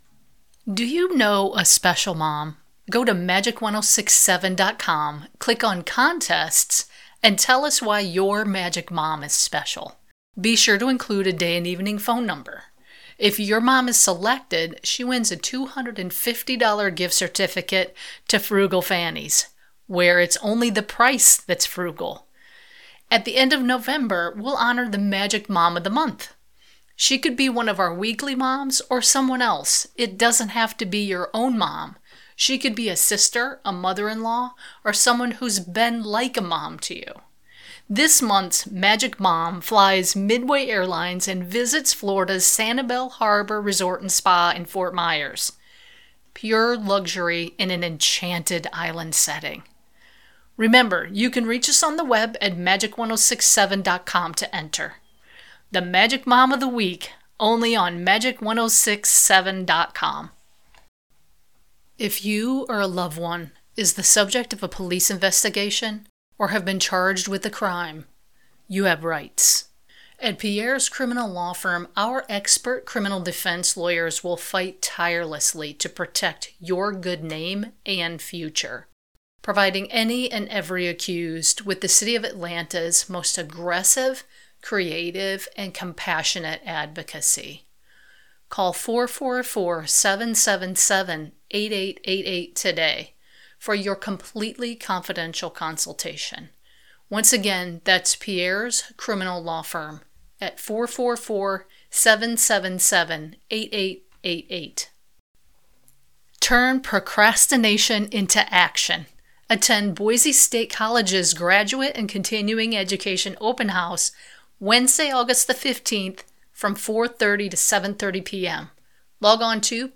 Commercial Sample Audio:
voiceover demo of three different audio business commercial clips of varying lengths.